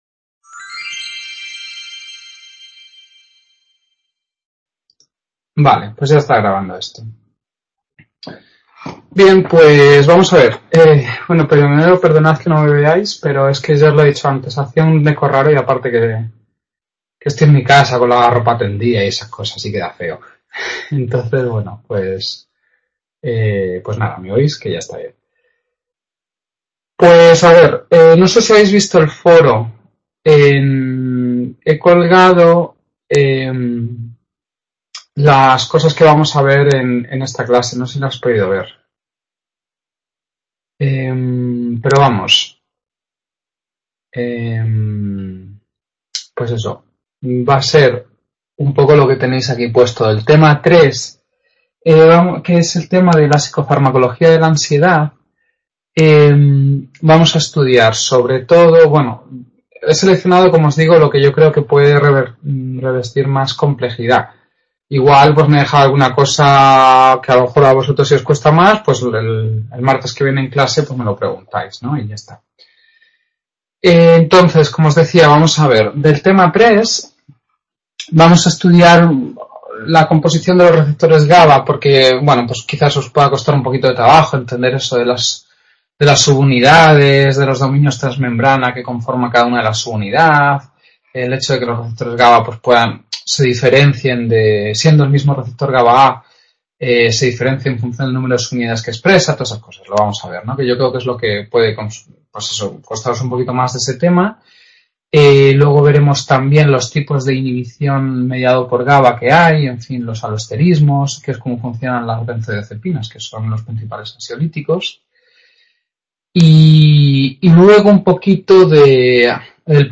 Clase 7 enero | Repositorio Digital